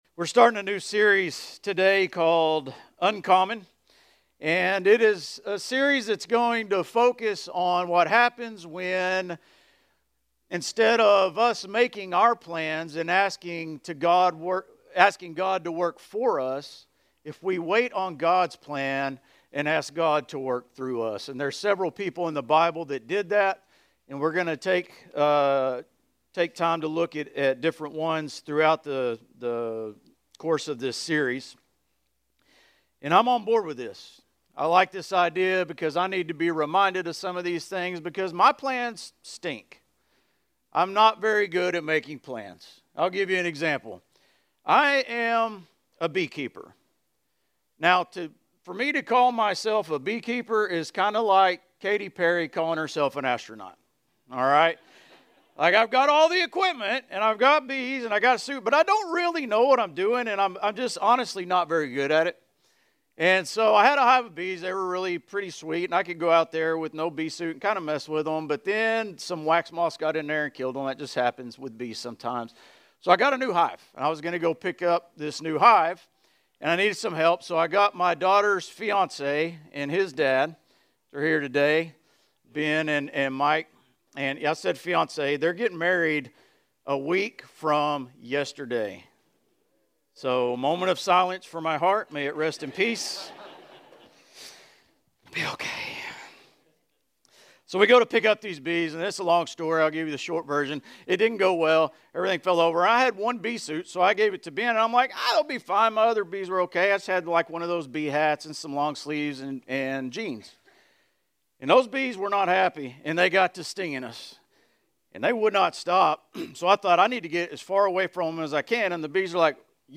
Grace Community Church Dover Campus Sermons 6_22 Dover Campus Jun 22 2025 | 00:37:47 1x 00:00 / 00:37:47 Subscribe Share RSS Feed Share Link Embed